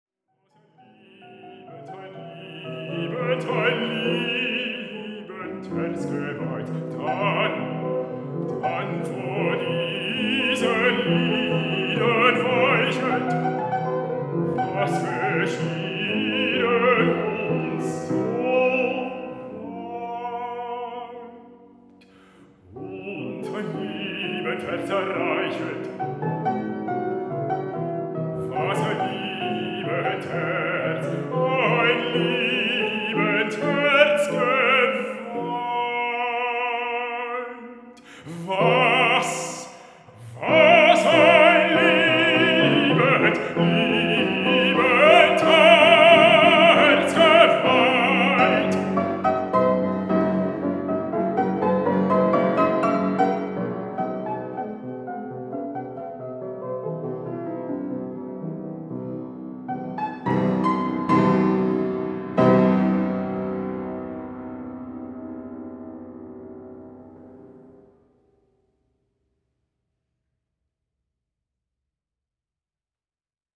Flying Tonstudio ist spezialisiert auf Tonaufnahmen vor Ort – für CD-Album oder Konzertmitschnitte bei Auftritten in Konzertsälen, Theater, Kirchen und anderen Veranstaltungsorten.